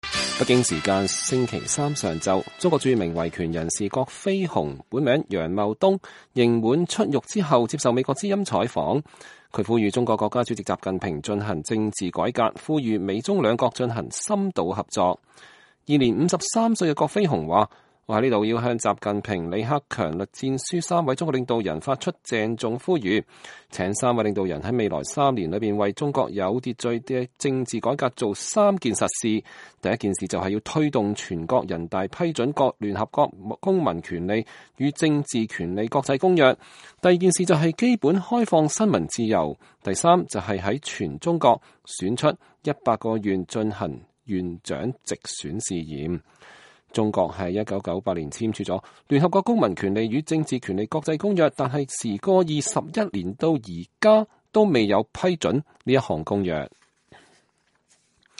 北京時間星期三上午，中國著名維權人士郭飛雄（本名楊茂東）刑滿出獄後接受美國之音採訪，他呼籲中國國家主席習近平進行政治改革，呼籲美中兩國進行深度合作。
郭飛雄是在服刑6年、出獄後一個多小時的時候，通過長途電話接受美國之音採訪的。